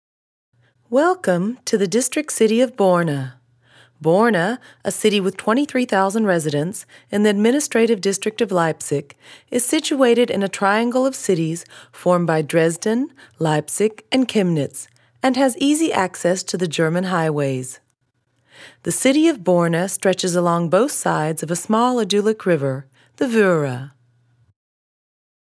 native American English speaker. Industrie/ eLearning/Werbung/ promotion
Sprechprobe: eLearning (Muttersprache):